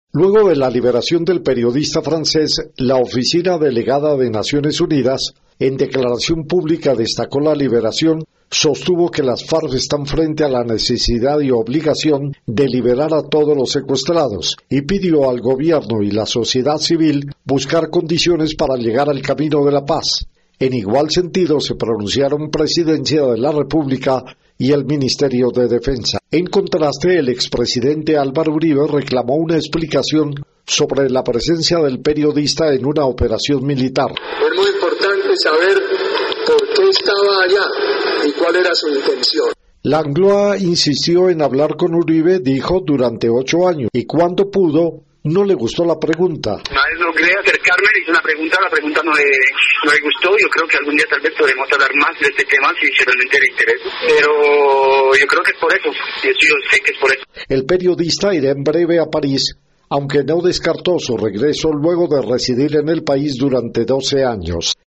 Las declaraciones de ambos en el informe para Radio Martí